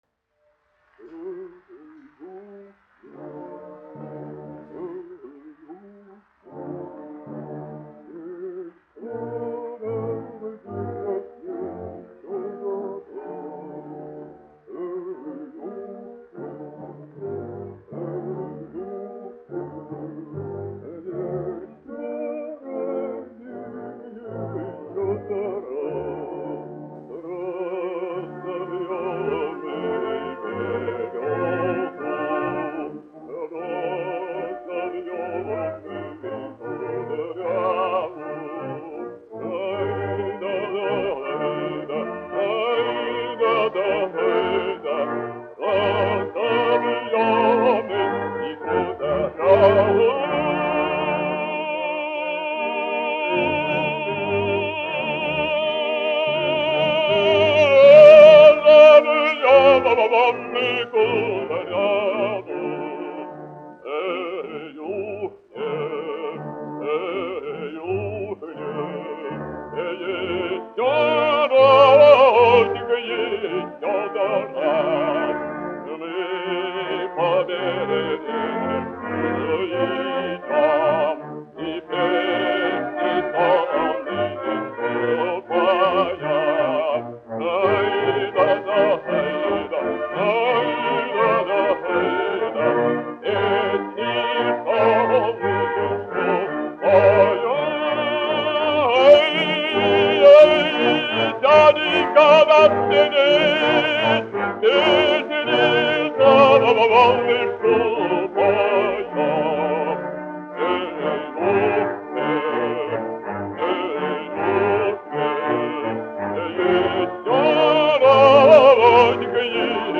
Шаляпин, Федор Иванович, 1873-1938, dziedātājs
1 skpl. : analogs, 78 apgr/min, mono ; 25 cm
Krievu tautasdziesmas
Skaņuplate
Latvijas vēsturiskie šellaka skaņuplašu ieraksti (Kolekcija)